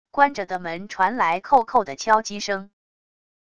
关着的门传来扣扣的敲击声wav音频